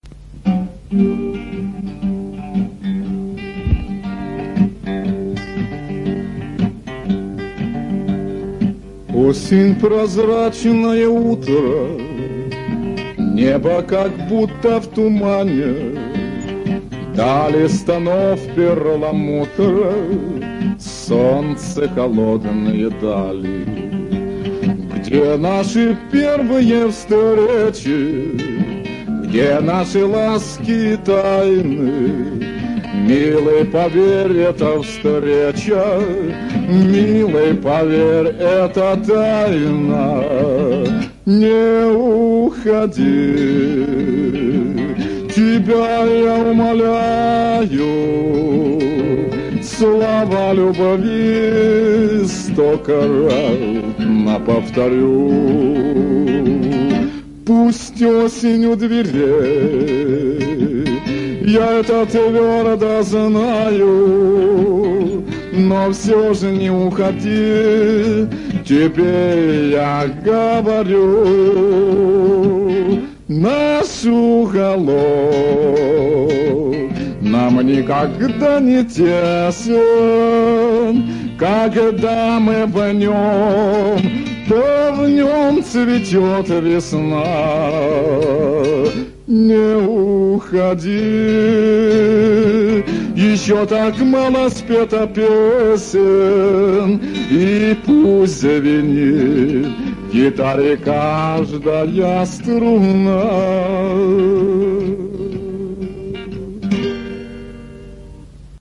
С южным акцентиком, как и положено для старомодных песен.